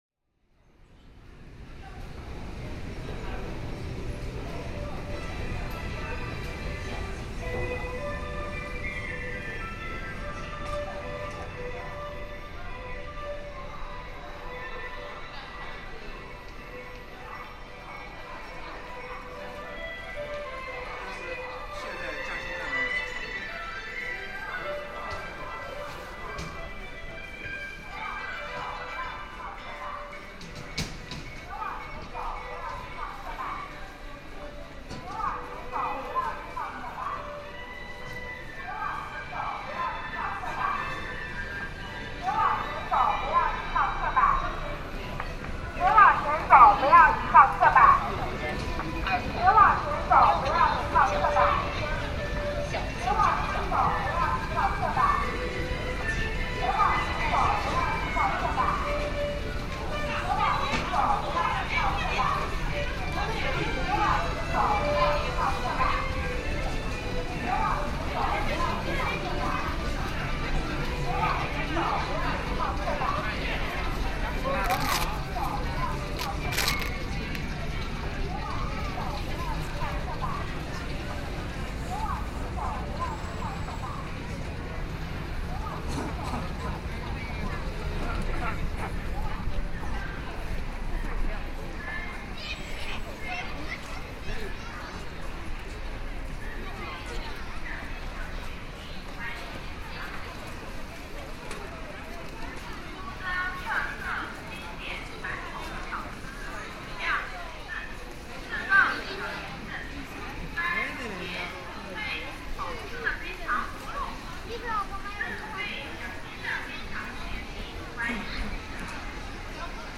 Various shops play different music to attract customers, creating a patchwork of sounds that defines the urban soundscape. From loudspeakers, I heard pre-recorded voices, likely sales pitches intended to lure passersby into making a purchase. Some shop assistants amplified their own voices with megaphones, adding to the cacophony. Amidst this, snippets of cheerful conversations between people could be discerned, punctuated by the occasional long yawn, a clue that someone must have been really tired. UNESCO listing: China’s Spring Festival